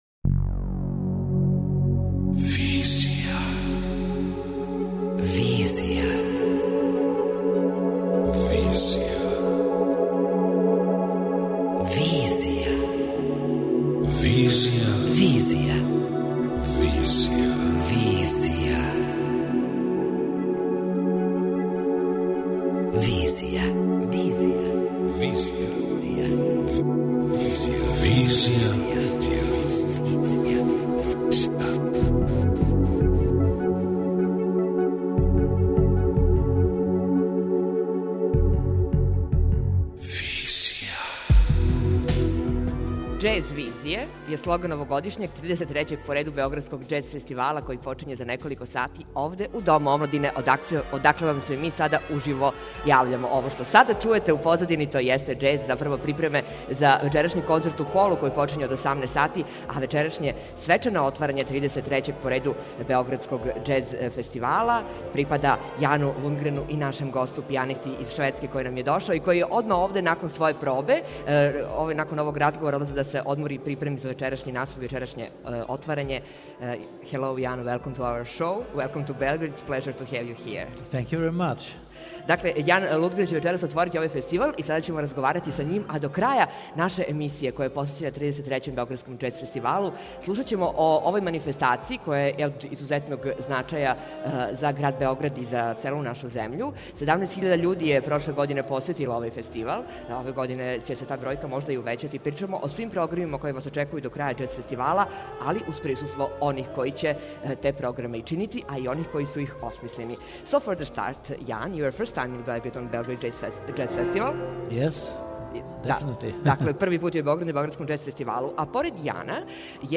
Pred svečano otvaranje 33. Beogradskog džez festivala realizujemo uživo emisiju iz Doma omladine.